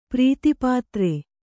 ♪ prītipātre